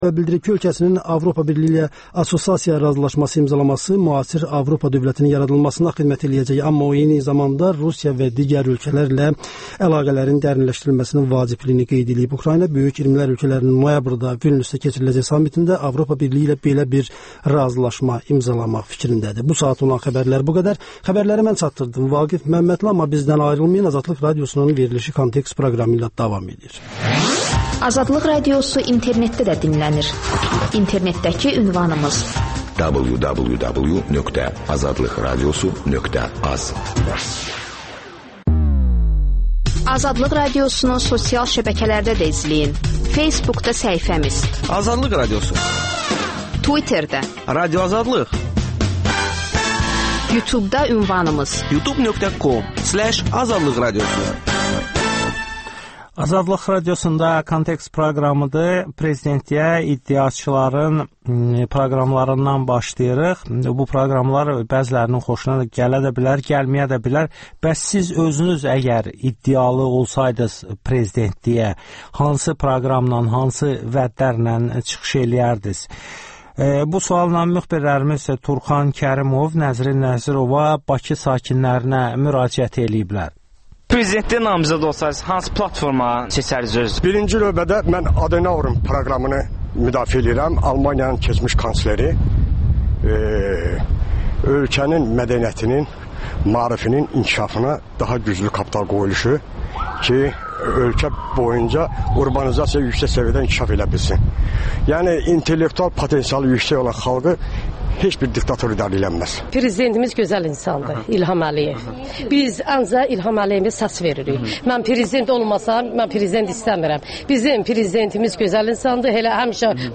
AzadlıqRadiosunun Bakıda keçirdiyi sorğu.